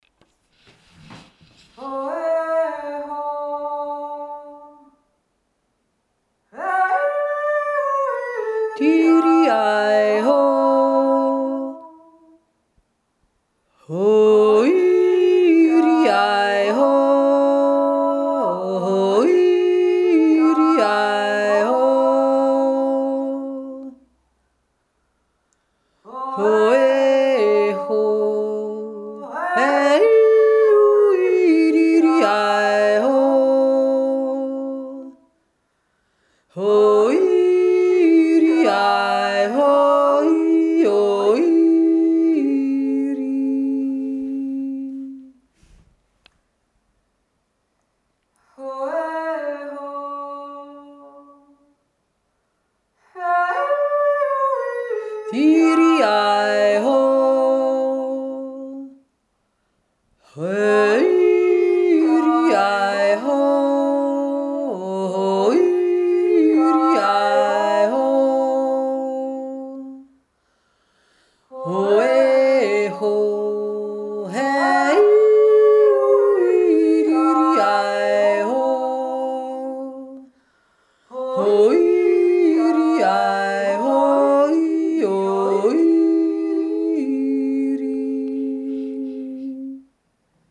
2. Stimme